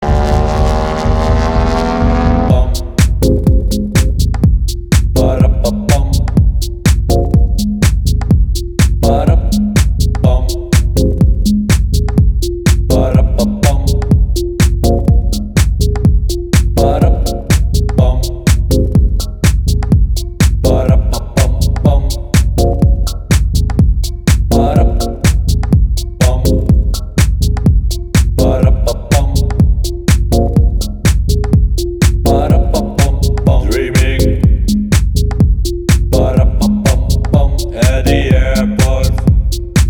• Качество: 320, Stereo
мужской голос
ритмичные
забавные
Tech House